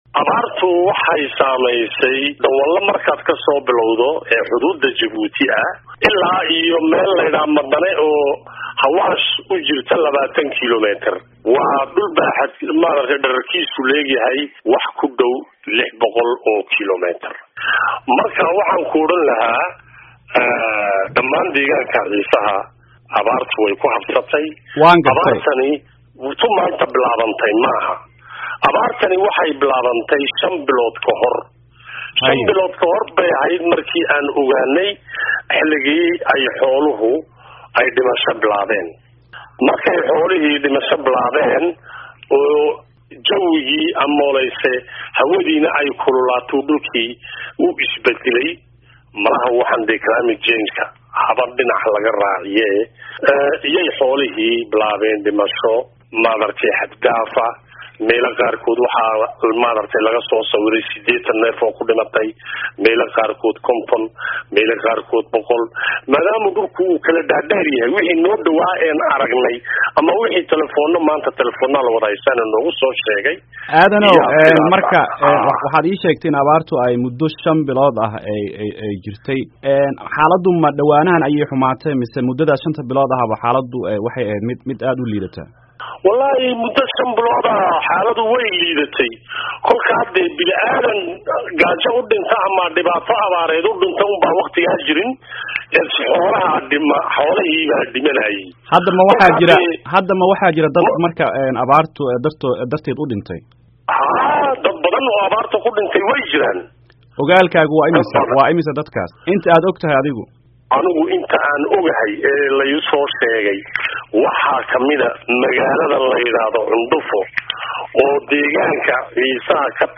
Wareysiga Abaarta